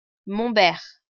Montbert (French pronunciation: [mɔ̃bɛʁ]